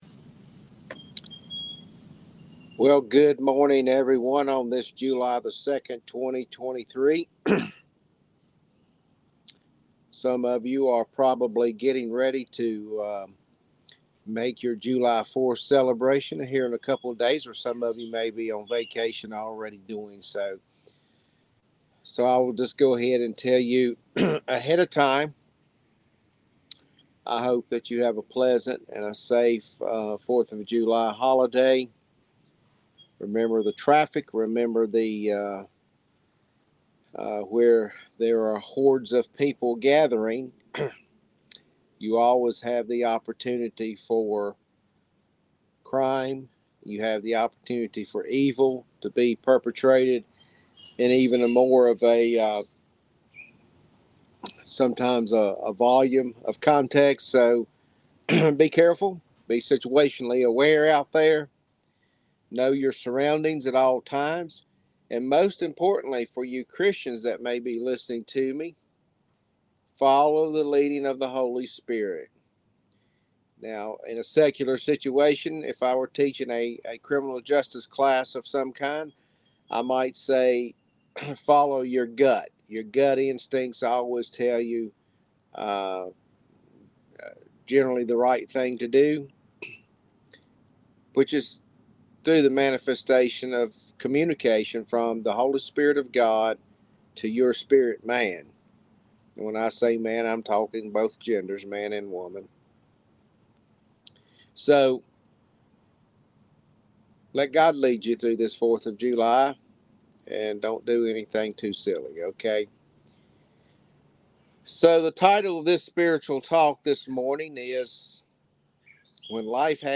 Spiritual Talk # 8